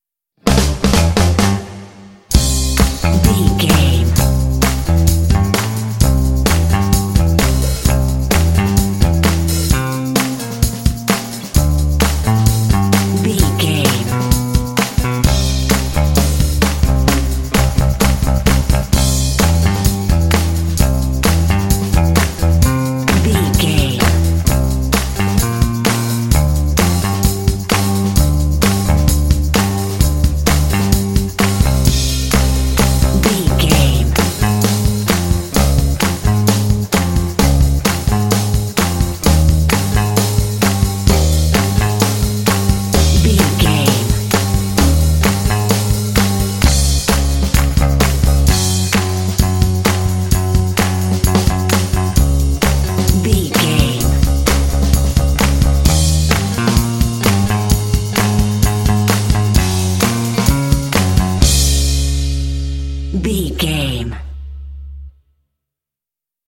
Ionian/Major
energetic
playful
lively
cheerful/happy
percussion
bass guitar
drums
classic rock